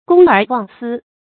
公耳忘私 注音： ㄍㄨㄙ ㄦˇ ㄨㄤˋ ㄙㄧ 讀音讀法： 意思解釋： 見「公爾忘私」。